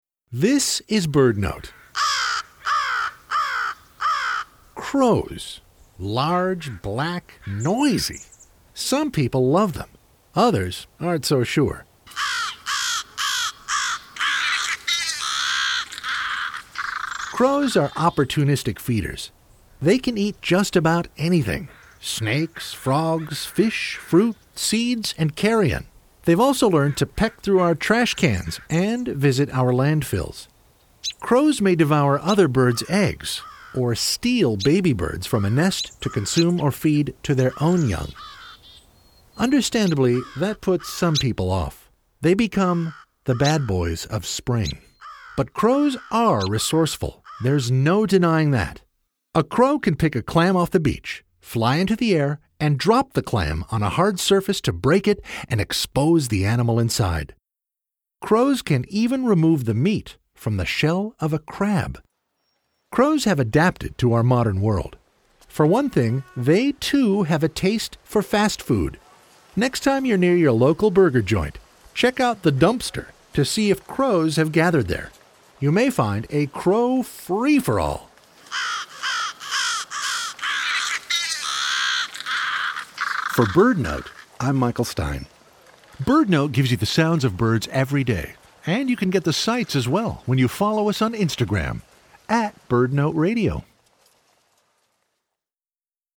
Large, black, noisy. The raucous birds of the neighborhood.